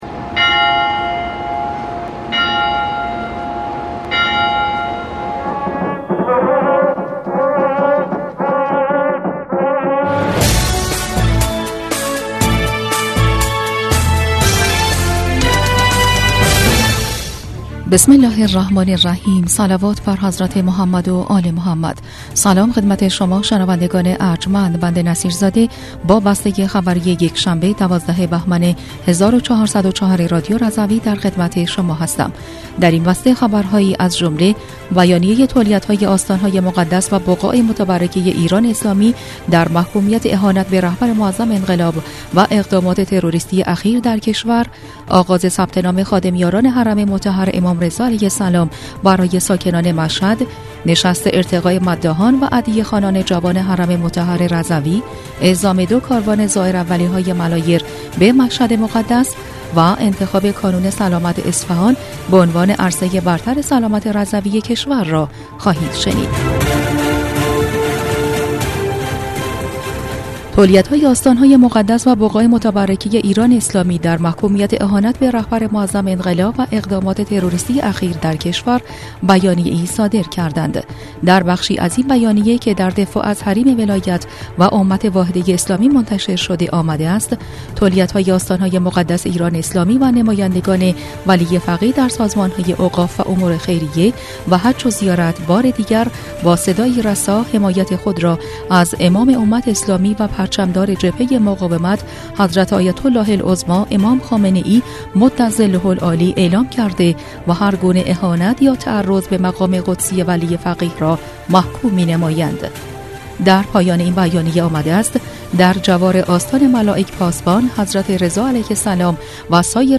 بسته خبری ۱۲ بهمن ۱۴۰۴ رادیو رضوی؛